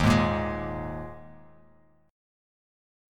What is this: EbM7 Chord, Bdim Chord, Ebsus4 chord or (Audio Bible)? Ebsus4 chord